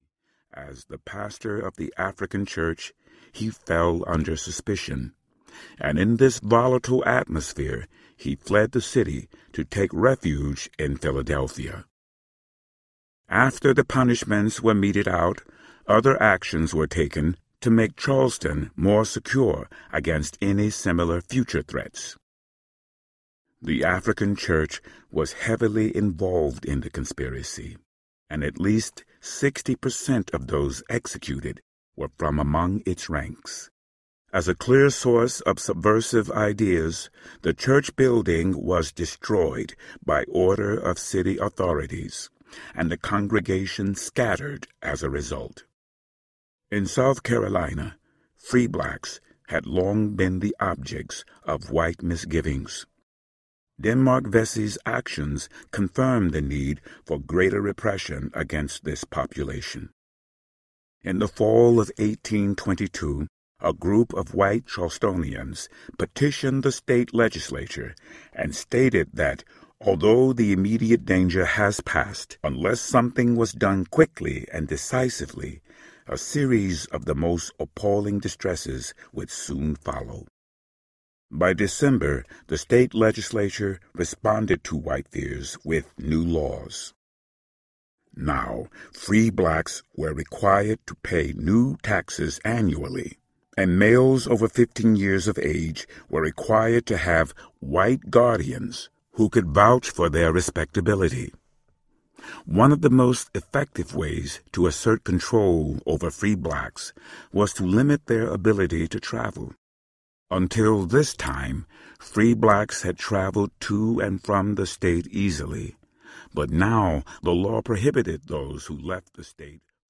We Are Charleston Audiobook